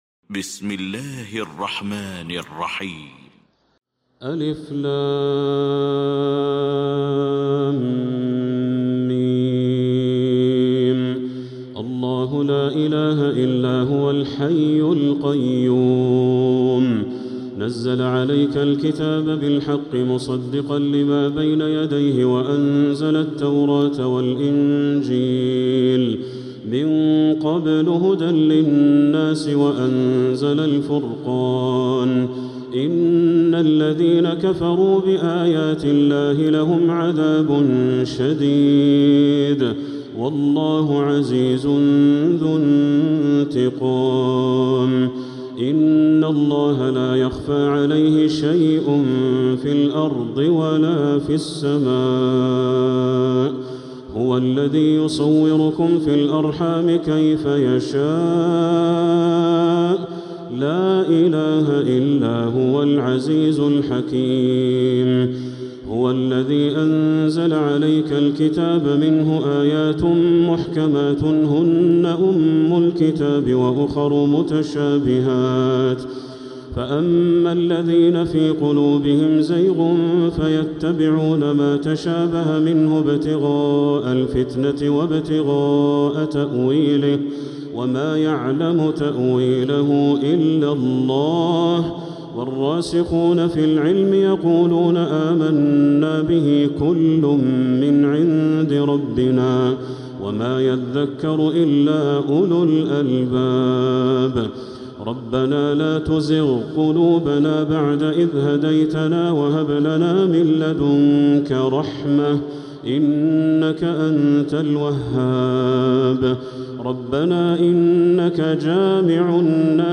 سورة آل عمران Surat Aal-i-Imraan > مصحف تراويح الحرم المكي عام 1447هـ > المصحف - تلاوات الحرمين